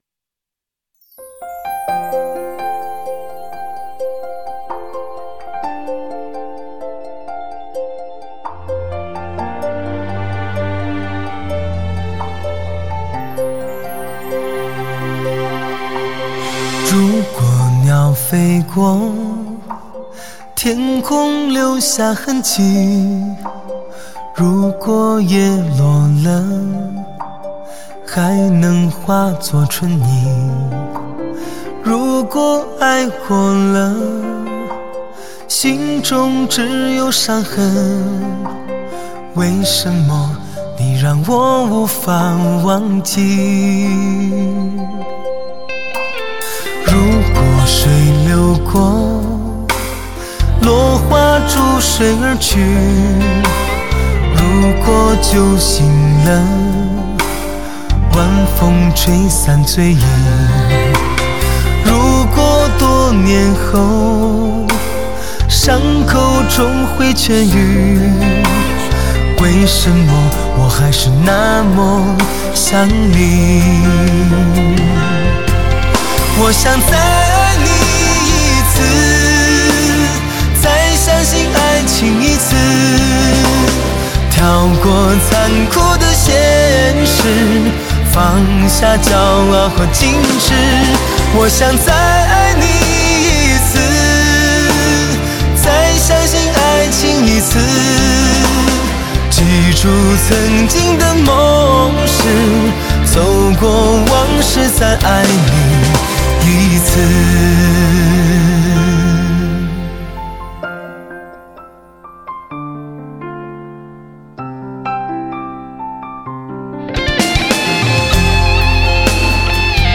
新专辑依然可见他惯用的风格，用男人最深情的倾诉诠释着所有人的爱情感伤，歌曲中每一个音符都饱含着温暖的诉说。